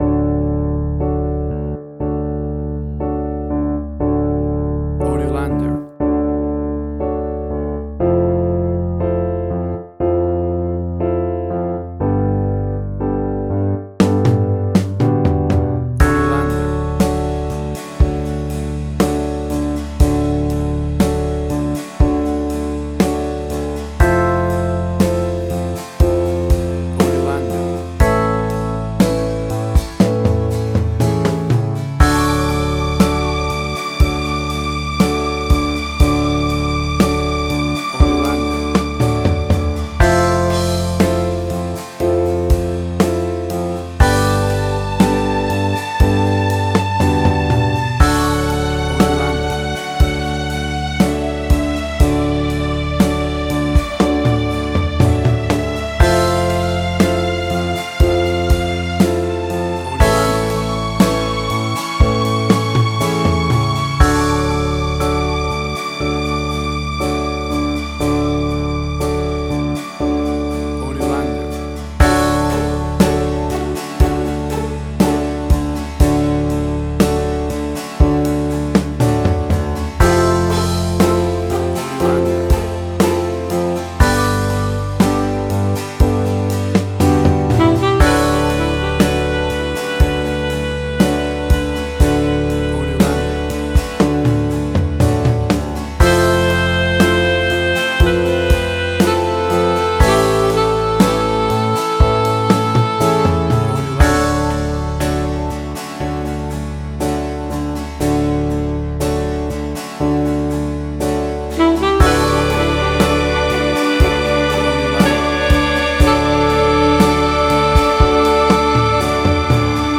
Indie Quirky
Tempo (BPM): 60